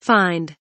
find kelimesinin anlamı, resimli anlatımı ve sesli okunuşu